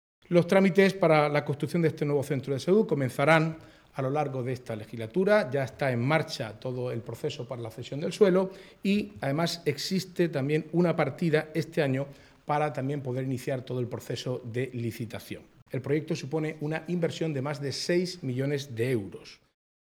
Declaraciones del consejero de Presidencia, Portavocía y Acción Exterior, Marcos Ortuño, sobre el nuevo centro de salud que la Comunidad prevé construir en Santiago de La Ribera (San Javier).